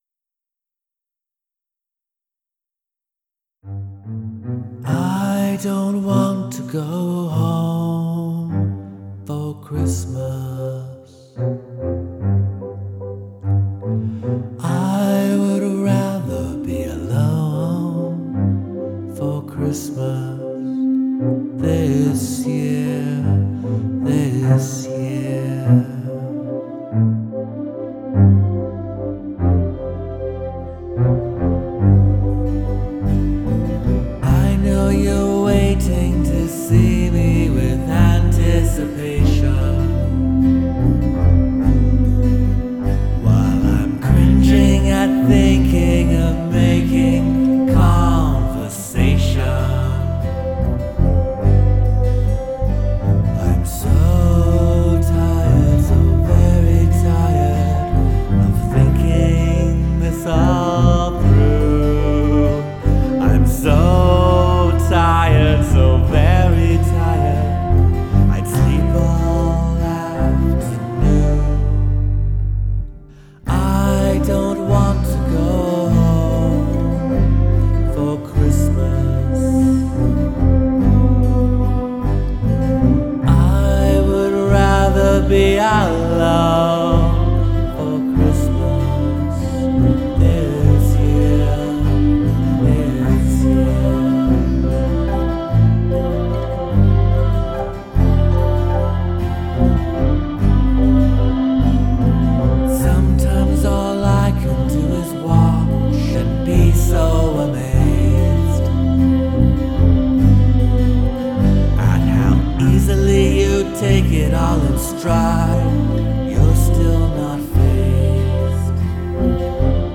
Indie music (e.g. Smiths / Cure Fans)